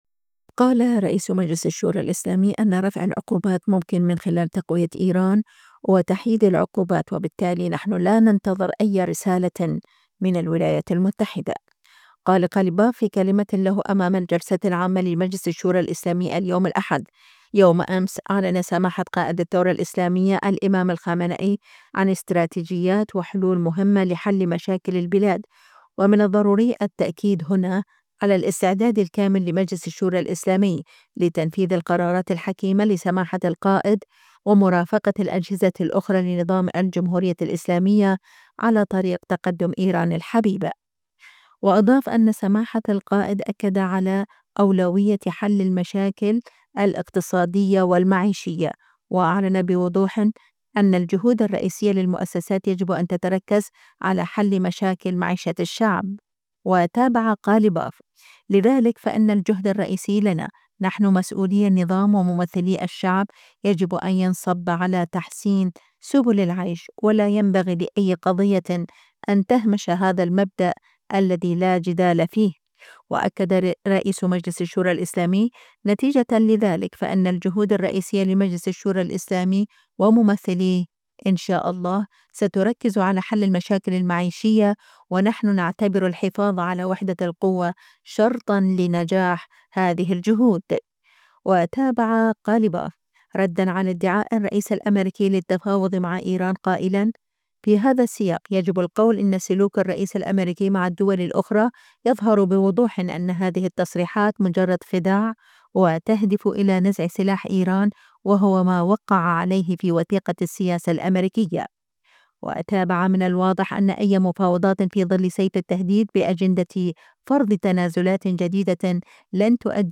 قال قاليباف في كلمة له أمام الجلسة العامة لمجلس الشورى الإسلامي اليوم (الأحد ): "يوم أمس، أعلن سماحة قائد الثورة الإسلامية الامام الخامنئي عن استراتيجيات وحلول مهمة لحل مشاكل البلاد، ومن الضروري التأكيد هنا على الاستعداد الكامل لمجلس الشورى الإسلامي لتنفيذ القرارات الحكيمة لسماحة القائد ومرافقة الأجهزة الأخرى لنظام الجمهورية الإسلامية على طريق تقدم إيران الحبيبة".